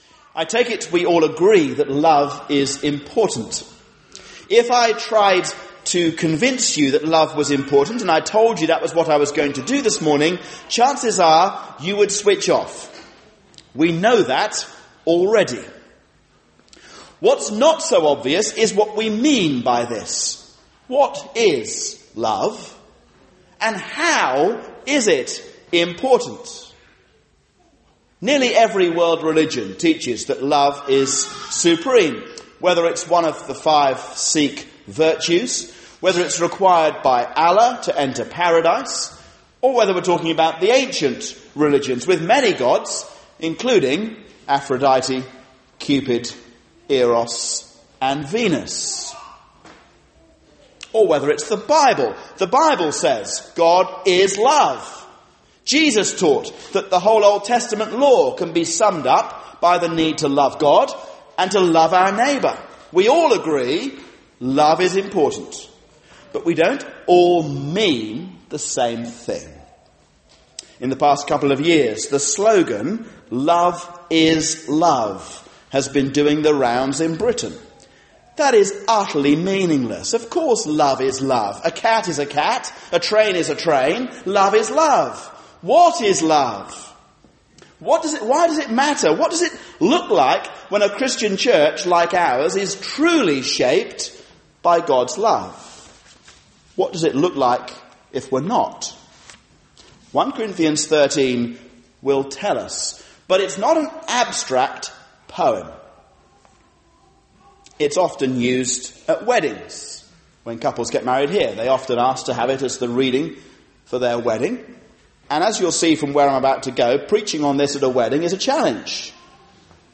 Love | Kemsing and Woodlands